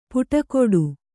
♪ puṭakoḍu